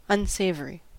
Uttal
Alternativa stavningar (brittisk engelska) unsavoury Synonymer odious Uttal US UK: IPA : /ʌnˈseɪv.ə(ɹ)i/ Ordet hittades på dessa språk: engelska Ingen översättning hittades i den valda målspråket.